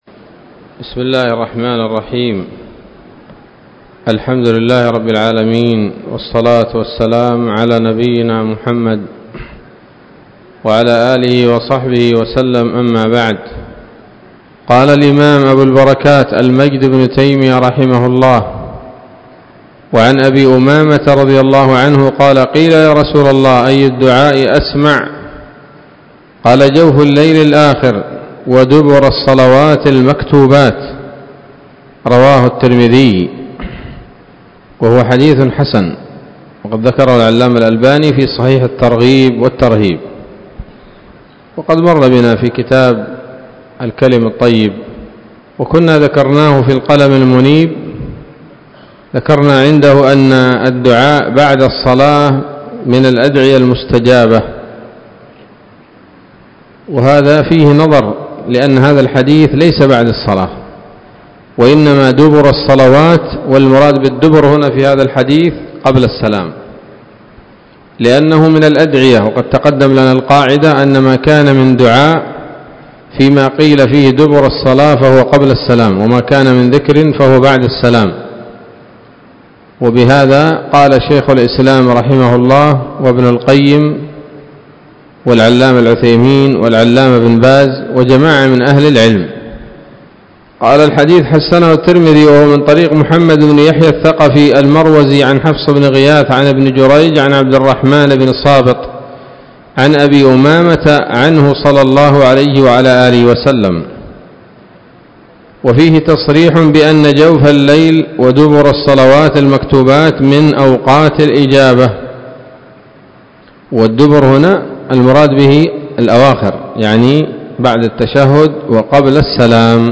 الدرس التاسع والتسعون من أبواب صفة الصلاة من نيل الأوطار